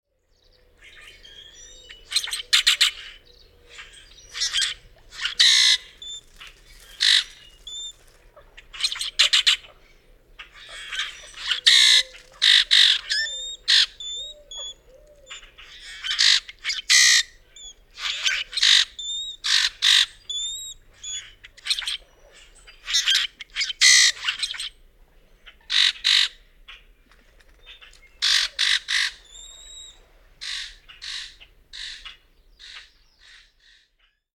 Common Grackle
common_grackle.mp3